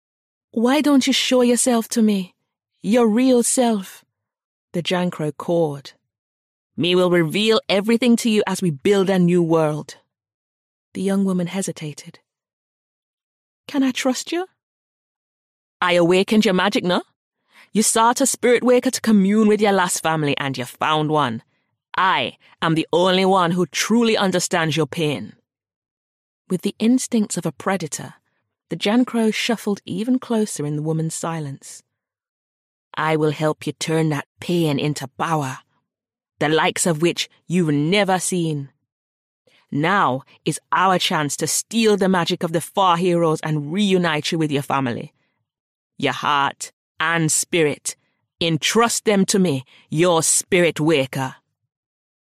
The audiobook is published by Saga Egmont and is available to pre-order now on Audible.